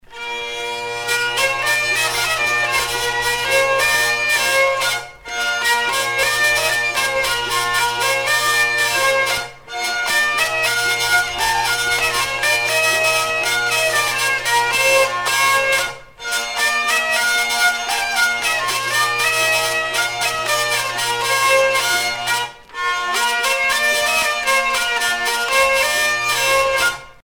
danse-jeu : trompeuse
Sonneurs de vielle traditionnels en Bretagne
Pièce musicale éditée